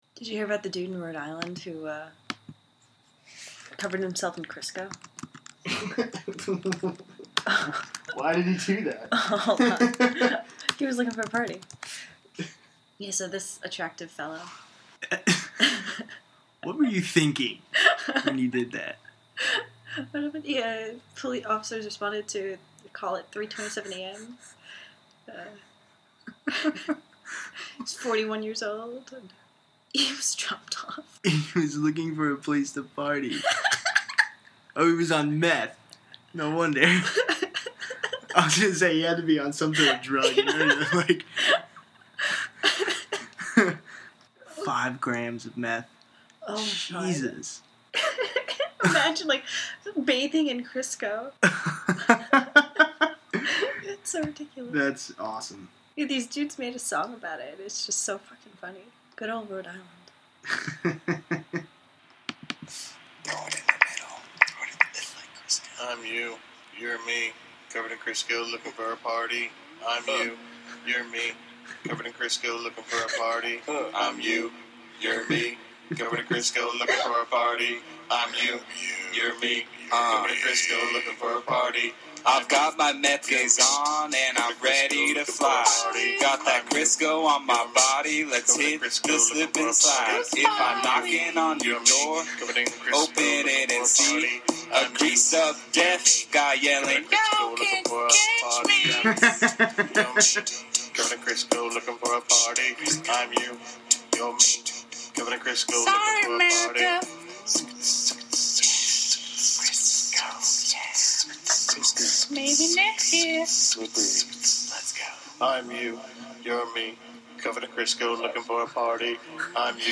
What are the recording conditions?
and uh.... here's an outtake!